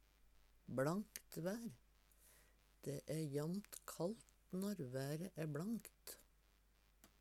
bLankt vær - Numedalsmål (en-US)